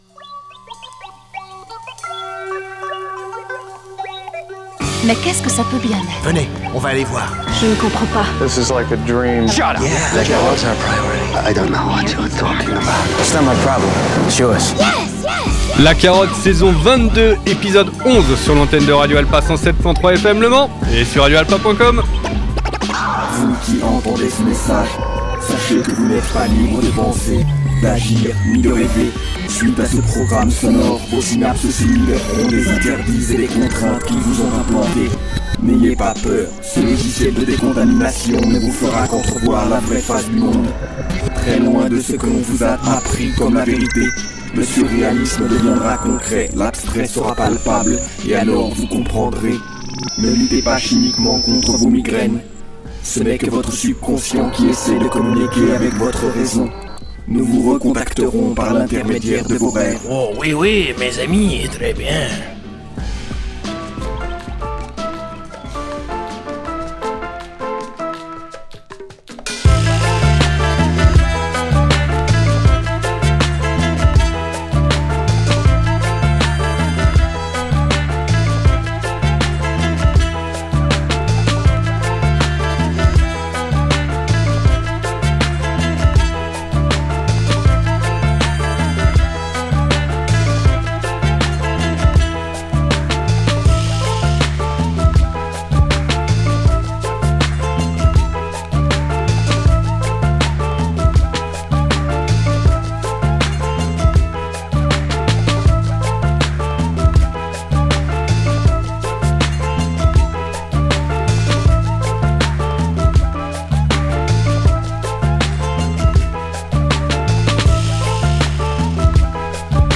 Ratatouille #2 // Une nouvelle cuvée de ratatouille rapologique est proposée avec l'addition de cépages 2023 et 2024 qui sont prédominants.
HIP-HOP